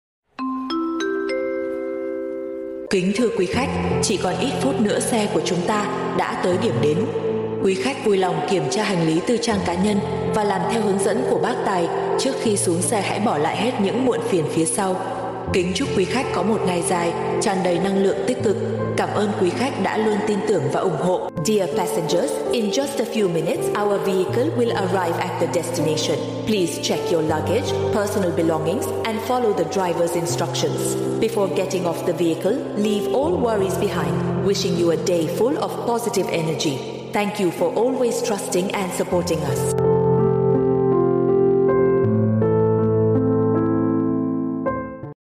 Âm thanh Carplay chào Xuống xe trả khách… cho xe Dịch Lịch Nhạc chuông GTA 5 Alarm
Thể loại: Tiếng chuông, còi
Description: Đây là lời chào mang tính thân thiện, lịch sự và chuyên nghiệp, thường được hệ thống CarPlay hoặc các thiết bị thông minh trên xe phát ra để nhắc nhở hành khách: Kính thưa quý khách, chỉ còn ít phút nữa, xe của chúng ta sẽ tới điểm đến.